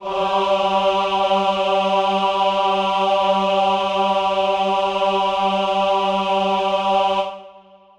Choir Piano
G3.wav